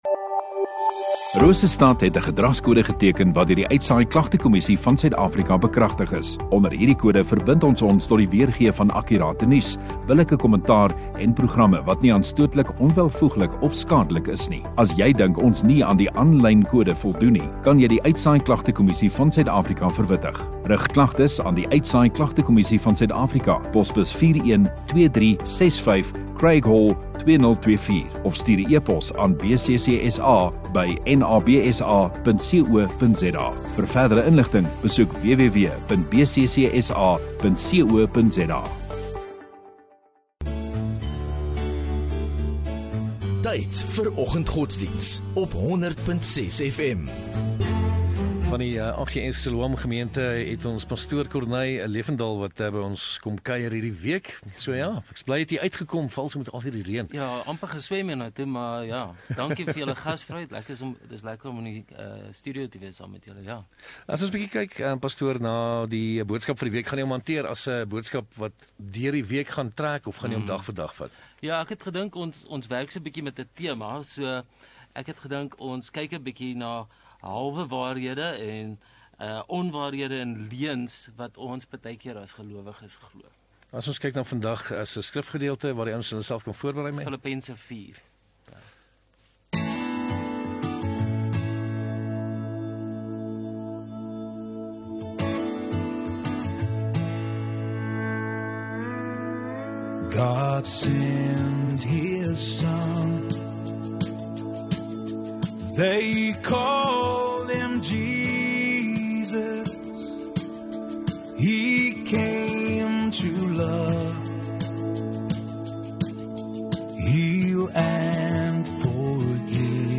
8 Apr Maandag Oggenddiens